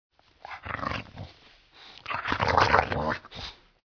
Звуки бульдога
Бульдог грозно рычит